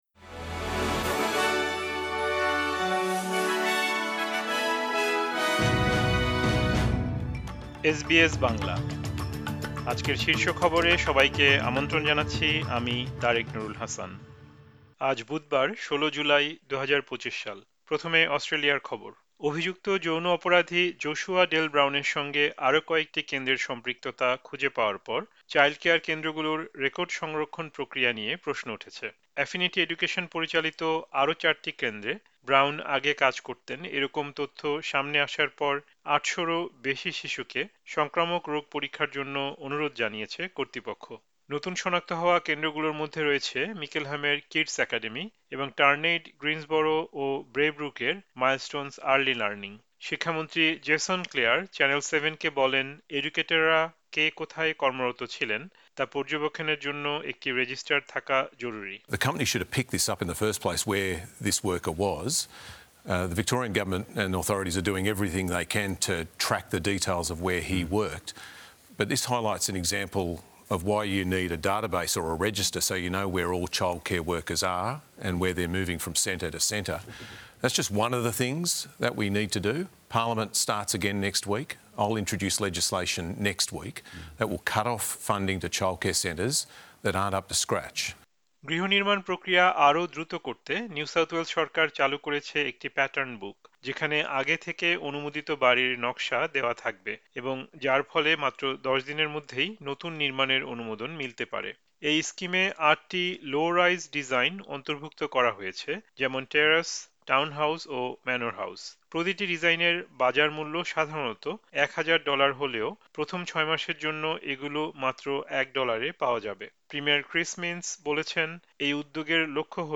এসবিএস বাংলা শীর্ষ খবর: ১৬ জুলাই, ২০২৫